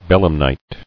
[bel·em·nite]